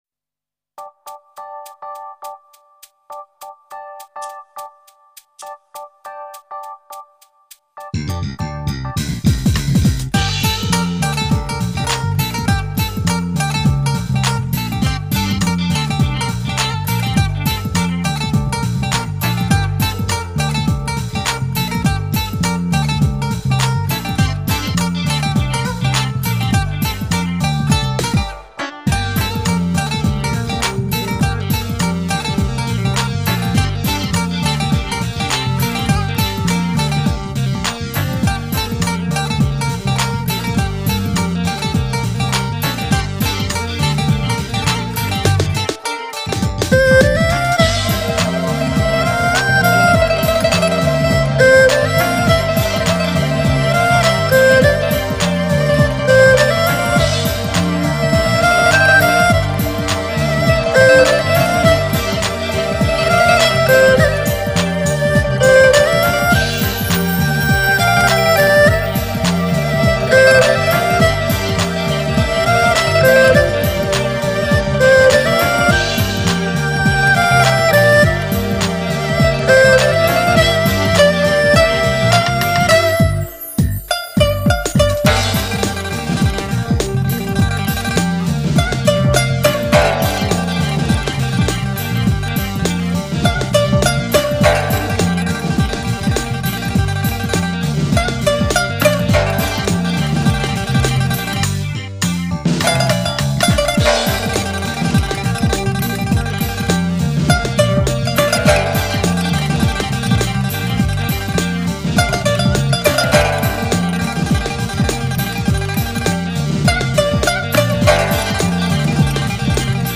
酸民乐，一种用民乐作为旋律乐器与电子舞曲、HIP-HOP相结合的音乐风格。
民乐+New Age+ Jazz+ Lounge，融合时尚元素，形成独具匠心的China Fusion。
民族调式构成的飘逸旋律，
让我们在G小调的天空中无拘无束的自由飘荡。